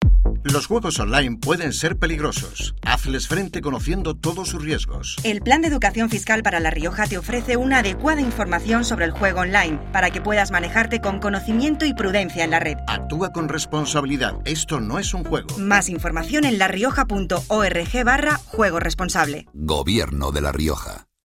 Elementos de campaña Cuñas radiofónicas Cuña genérica.